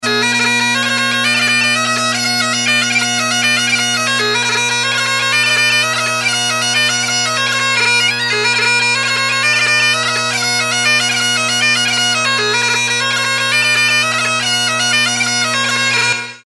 bagpipe reel